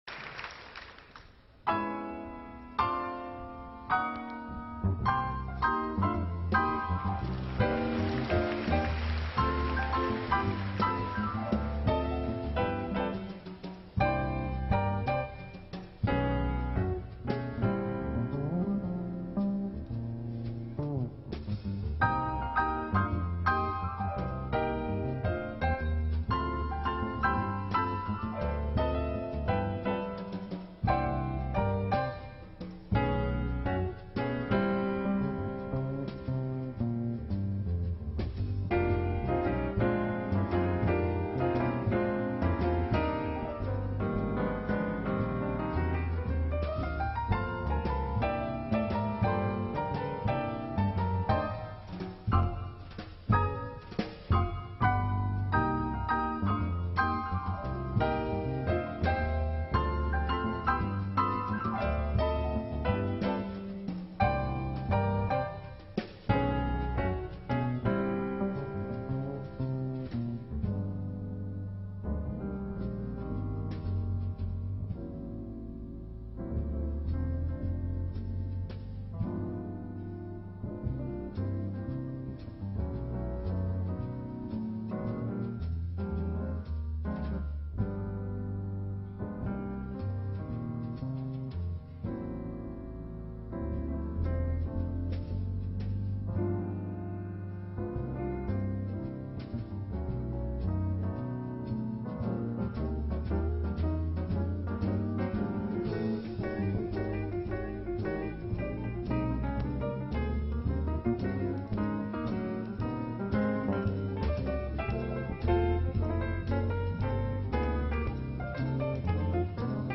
Ont was a Canadian jazz pianist and composer.